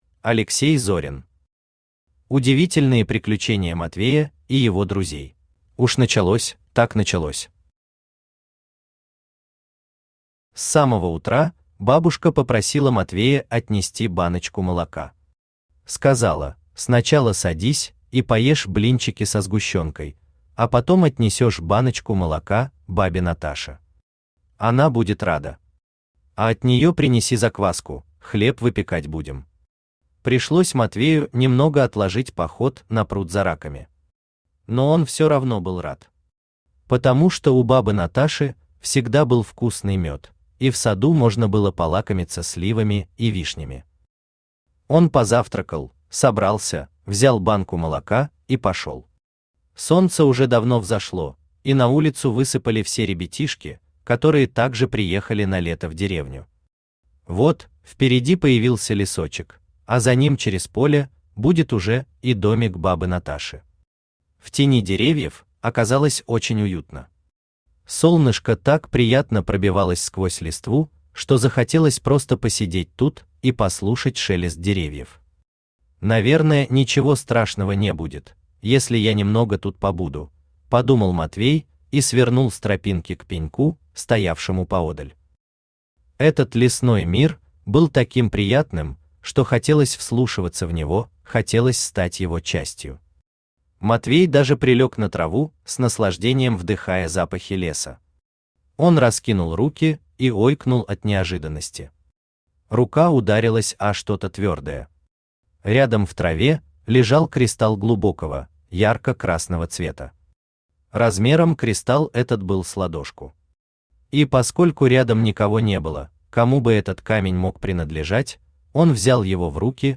Аудиокнига Удивительные приключения Матвея и его друзей | Библиотека аудиокниг
Aудиокнига Удивительные приключения Матвея и его друзей Автор Алексей Викторович Зорин Читает аудиокнигу Авточтец ЛитРес.